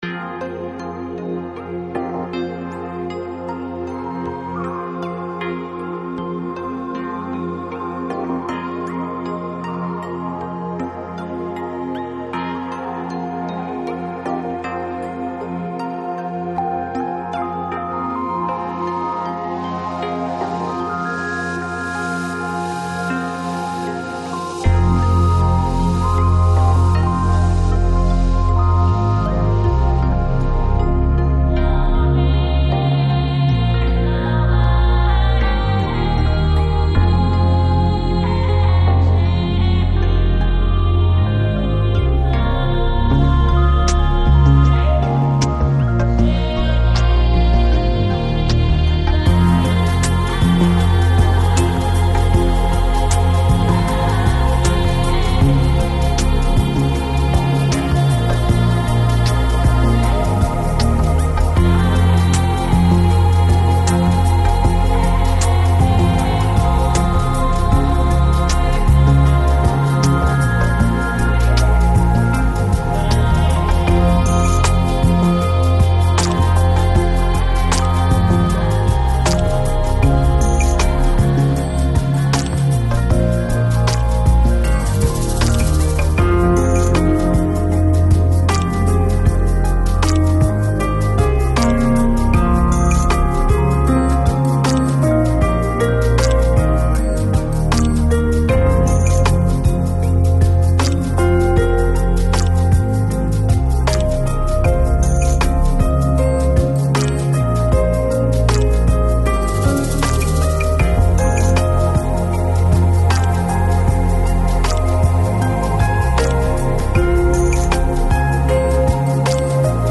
Жанр: Lounge, Lo-Fi, Ambient, New Age, Chill Out